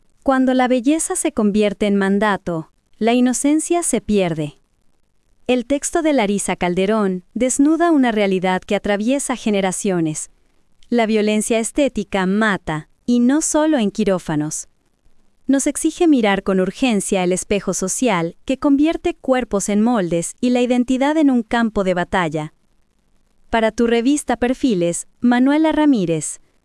🎧 COMENTARIO EDITORIAL